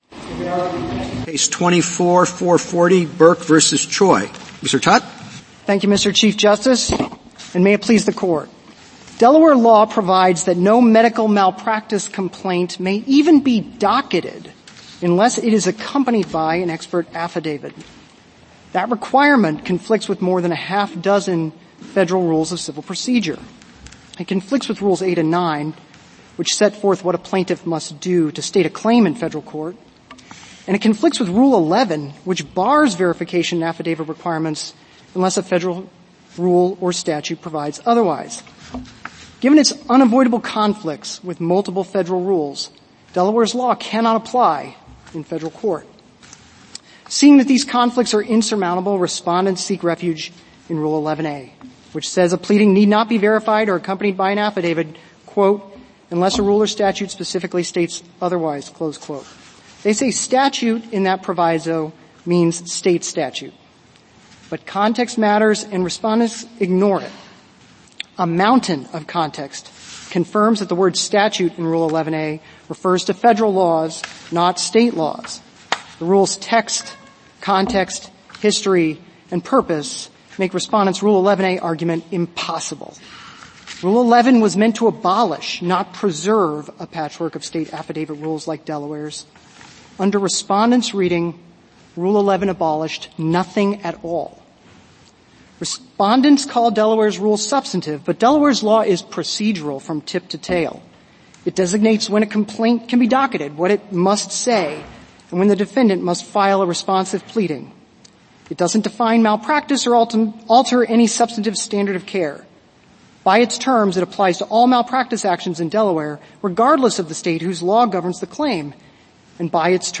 Supreme Court Oral Arguments